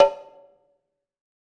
Index of /90_sSampleCDs/EdgeSounds - Drum Mashines VOL-1/M1 DRUMS